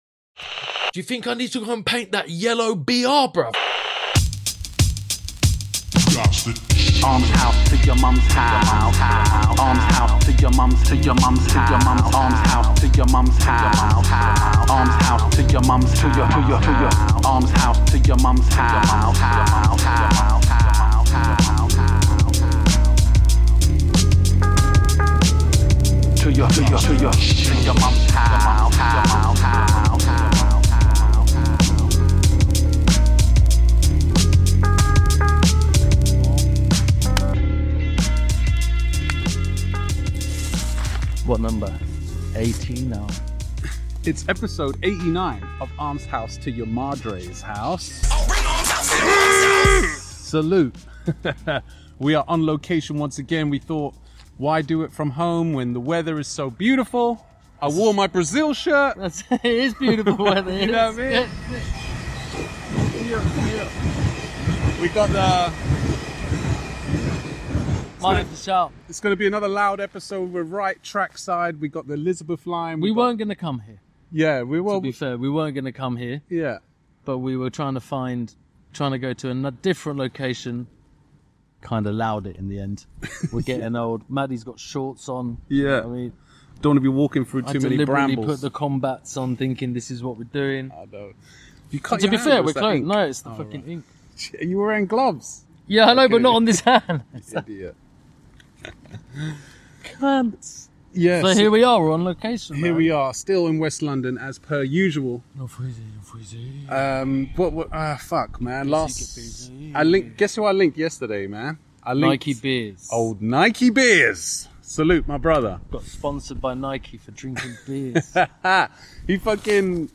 Yet another location episode trackside... The lads tell stories and critique peoples Graff and listen to tunes...